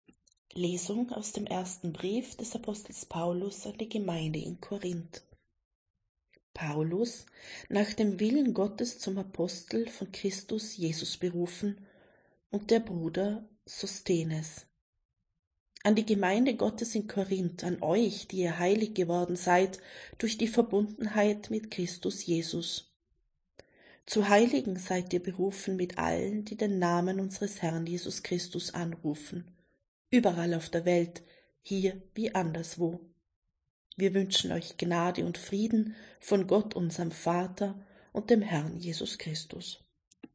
Wenn Sie den Text der 2. Lesung aus dem ersten ersten Brief des Apostels Paulus an die Gemeinde in Korínth anhören möchten: